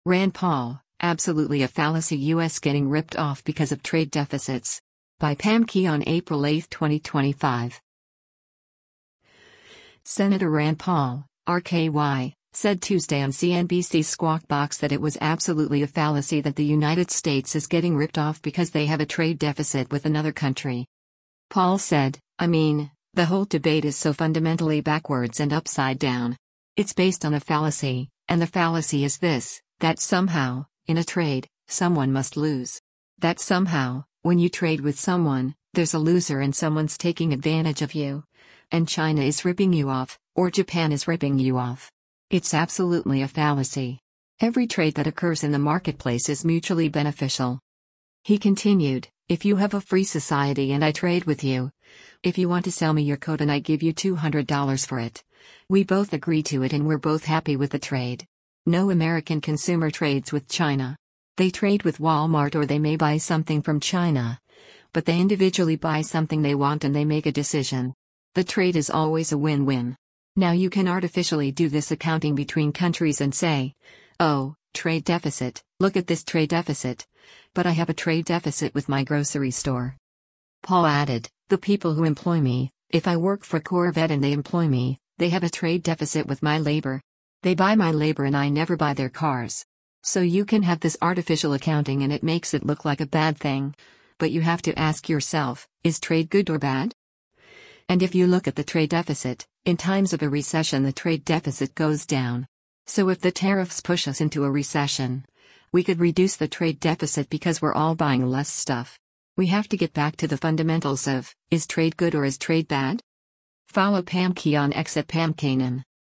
Senator Rand Paul (R-KY) said Tuesday on CNBC’s “Squawk Box” that it was “absolutely a fallacy” that the United States is getting ripped off because they have a trade deficit with another country.